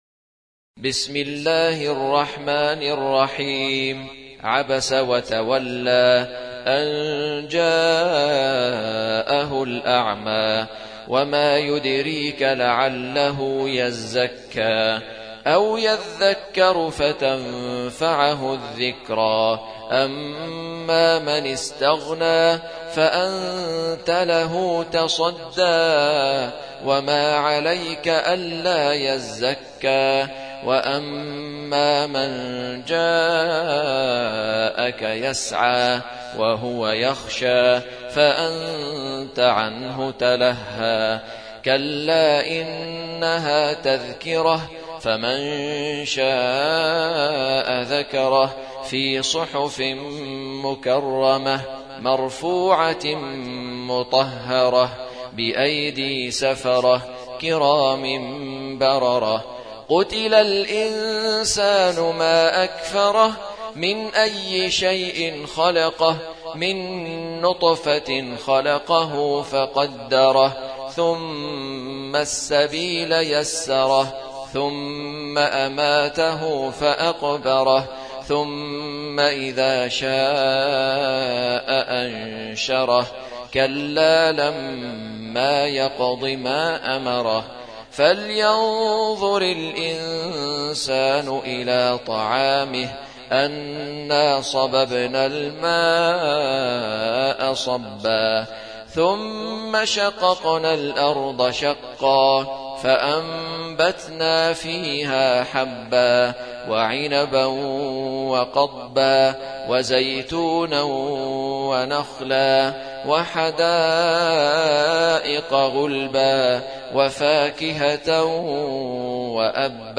Surah Sequence تتابع السورة Download Surah حمّل السورة Reciting Murattalah Audio for 80. Surah 'Abasa سورة عبس N.B *Surah Includes Al-Basmalah Reciters Sequents تتابع التلاوات Reciters Repeats تكرار التلاوات